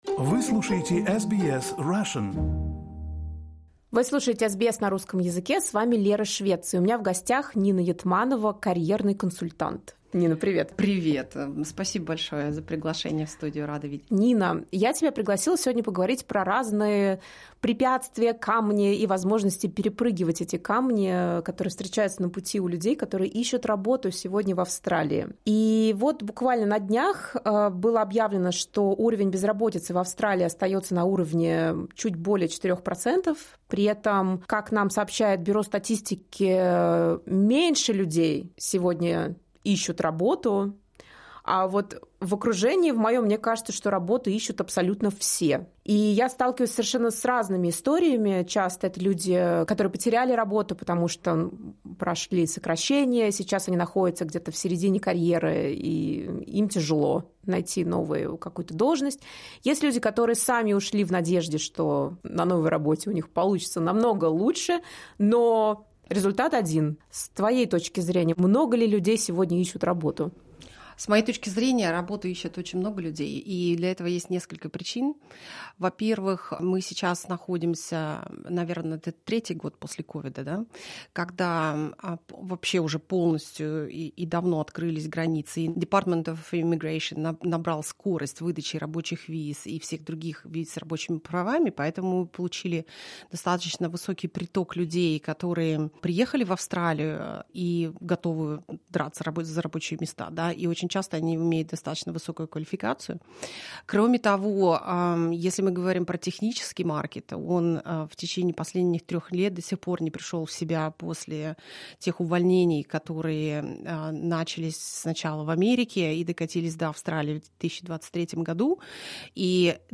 Больше историй, интервью и новостей от SBS Russian доступно здесь .